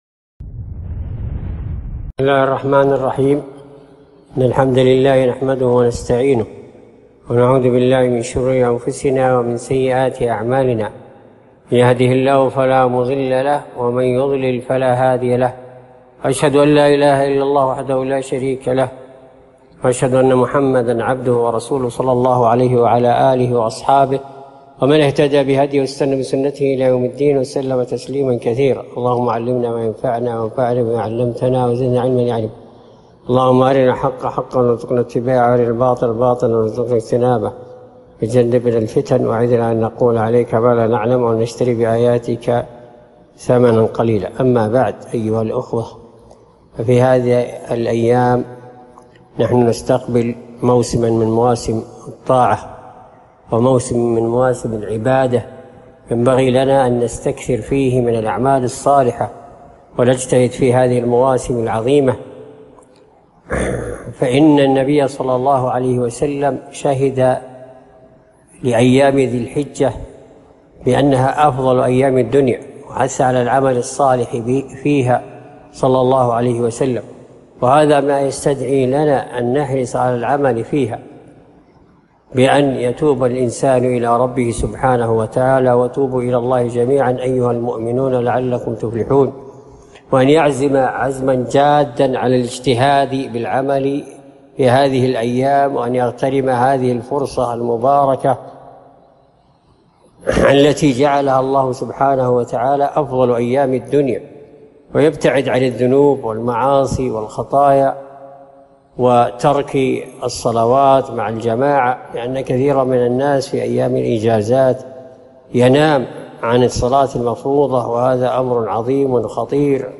كلمة - فضل عشر ذي الحجة